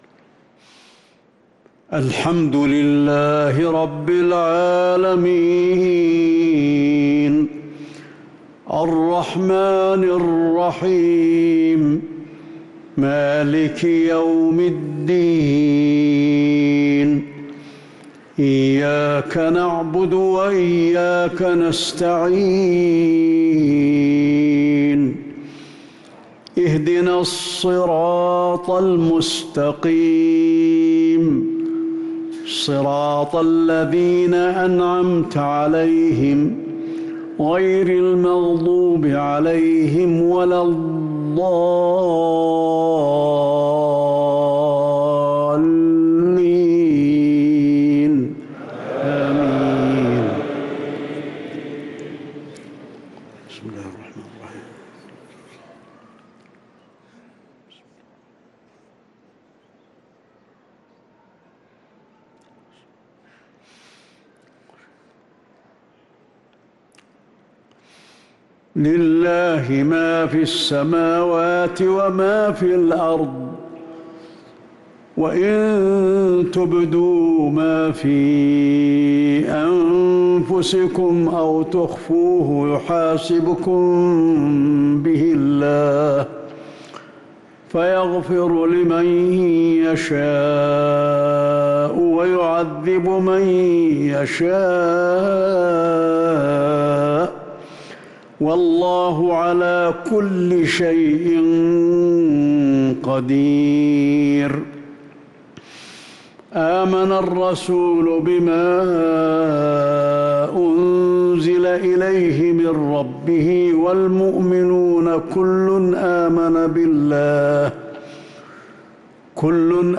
صلاة العشاء للقارئ علي الحذيفي 8 ربيع الأول 1445 هـ
تِلَاوَات الْحَرَمَيْن .